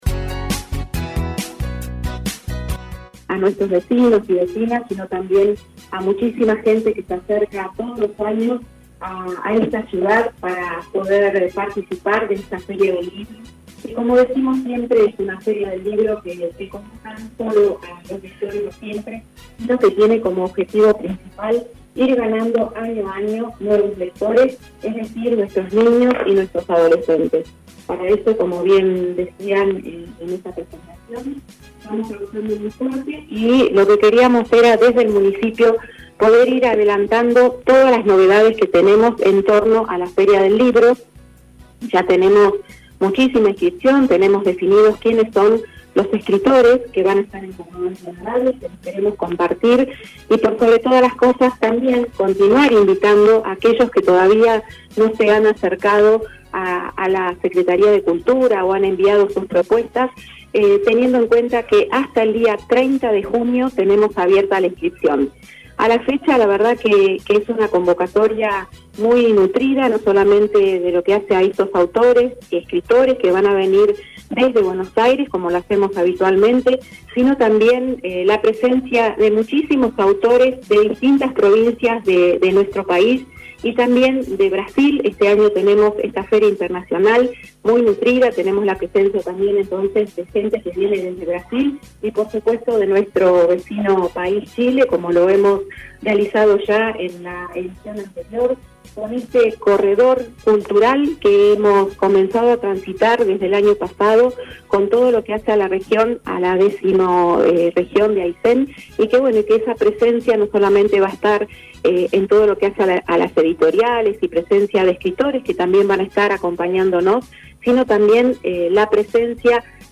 La secretaria de Cultura de nuestra ciudad Liliana Peralta encabezó la conferencia de presentación de la nueva edición de la Feria del Libro: